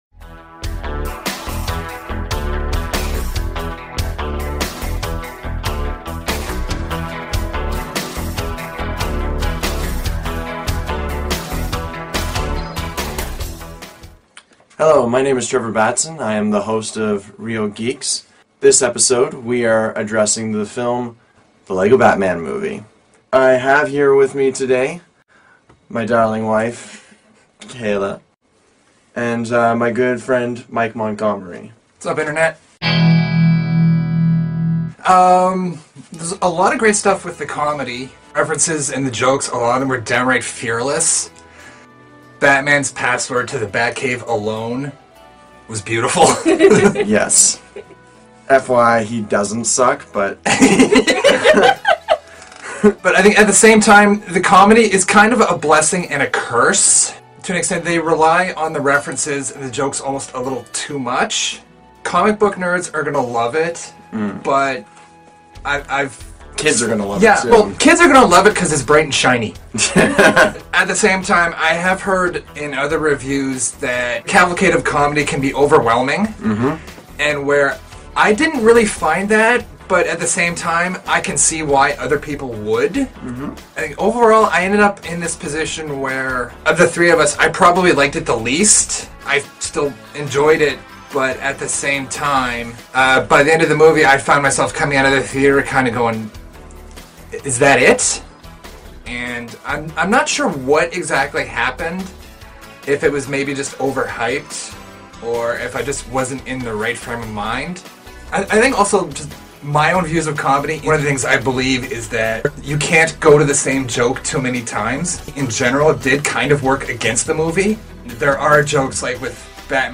Recorded in Halifax, NS, Canada